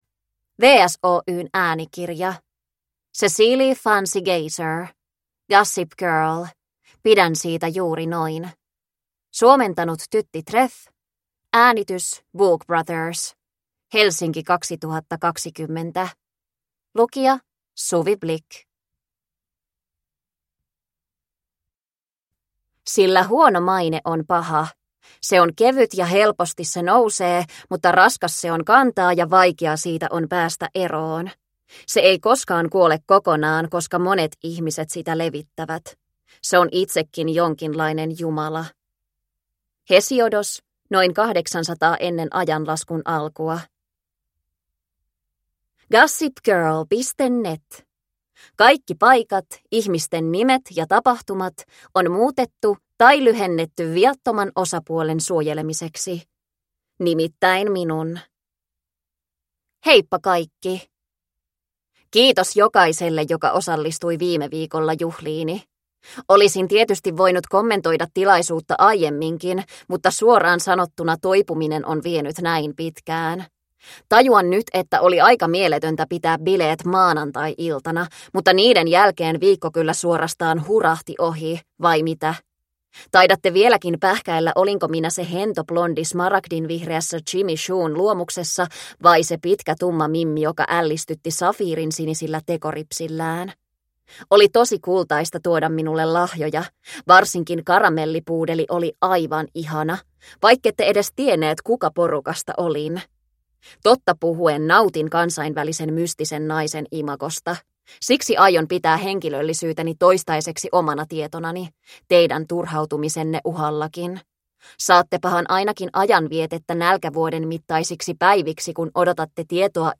Pidän siitä juuri noin – Ljudbok – Laddas ner